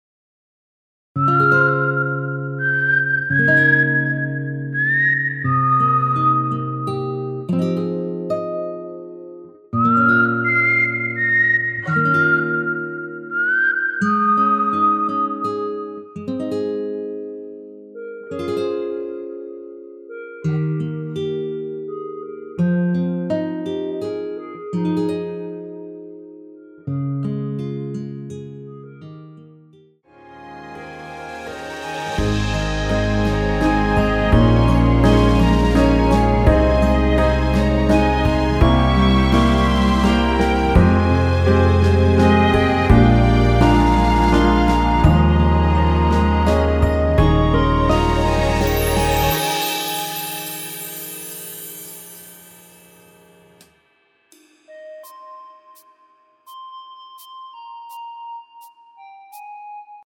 무반주 구간 들어가는 부분과 박자 맞출수 있게 쉐이커로 박자 넣어 놓았습니다.(미리듣기 확인)
원키 멜로디 포함된 MR입니다.(미리듣기 확인)
Ab
앞부분30초, 뒷부분30초씩 편집해서 올려 드리고 있습니다.
중간에 음이 끈어지고 다시 나오는 이유는